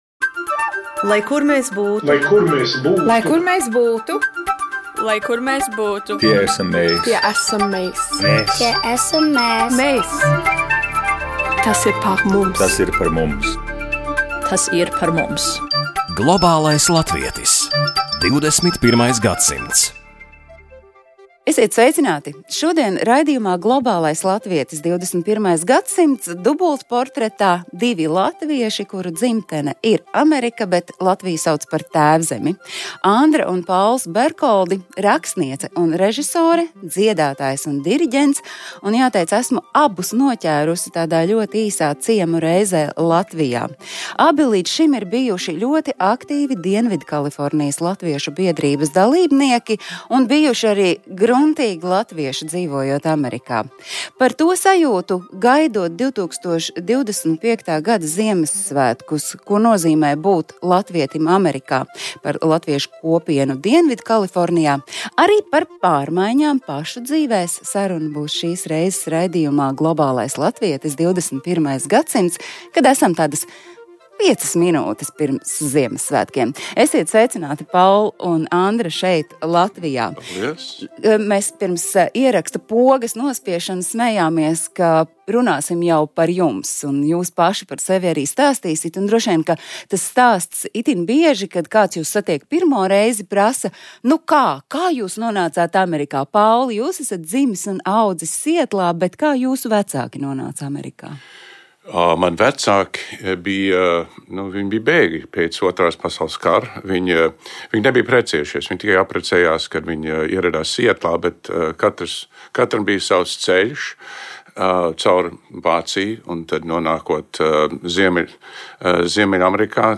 Raidījumā Globālais latvietis. 21. gadsimts dubultportretā divi latvieši, kuru dzimtene ir Amerika, bet Latviju viņi sauc par Tēvzemi.